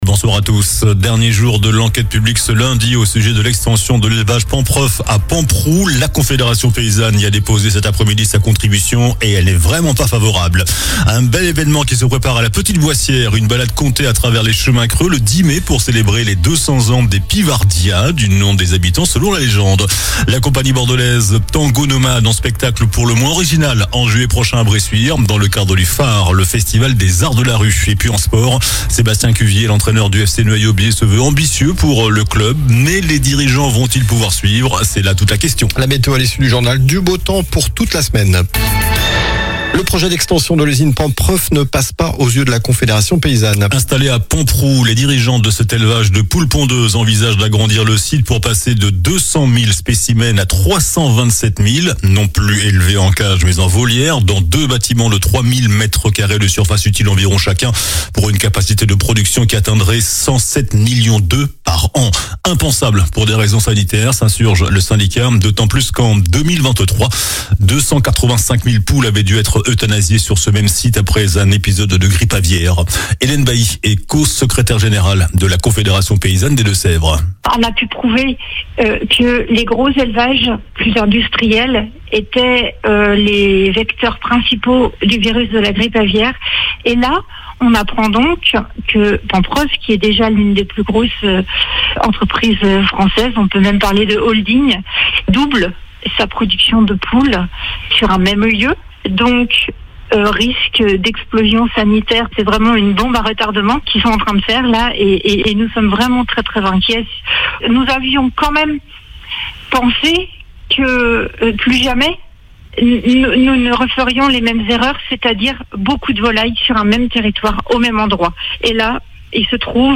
JOURNAL DU LUNDI 28 AVRIL ( SOIR )